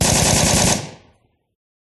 ガガガガ…。